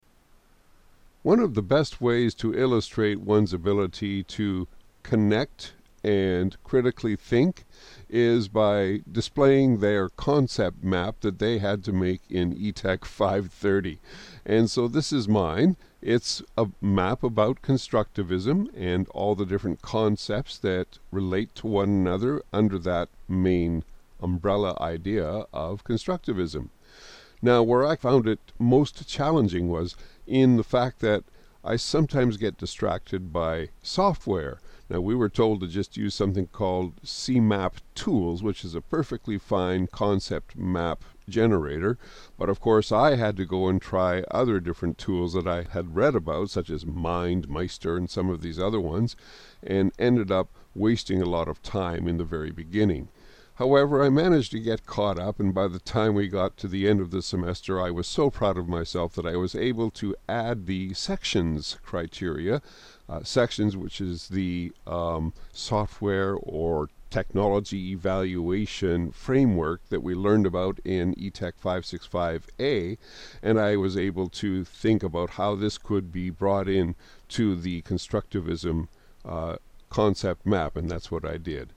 Reflection